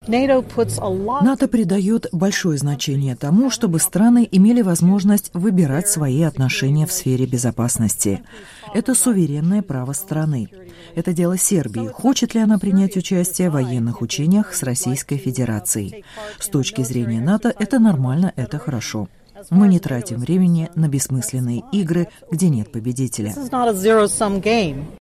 Заместитель Генерального секретаря НАТО Роуз Гетемюллер выступает на учениях в Черногории
Заместитель Генерального секретаря НАТО Роуз Гетемюллер, выступая в четверг перед журналистами в селе Плавница в Черногории, заявила, что у Сербии существует суверенное право выбирать, с кем поддерживать свои военные связи.